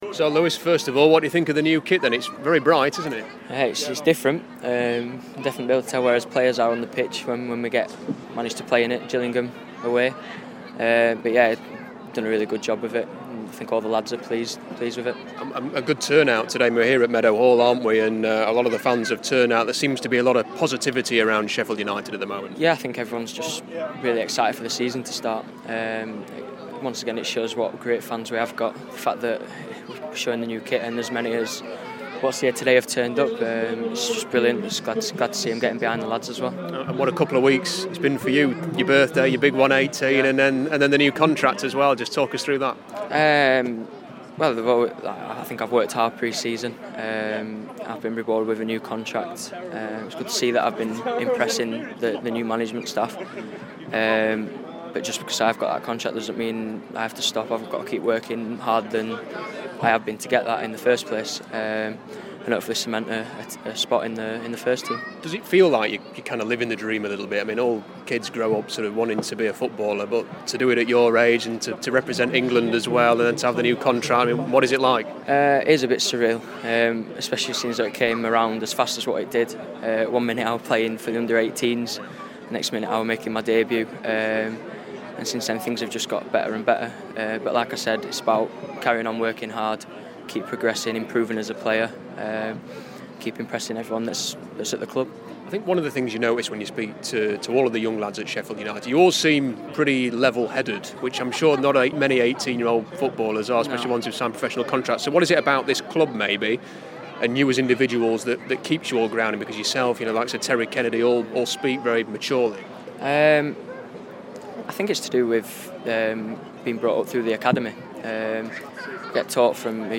Football Heaven / INTERVIEW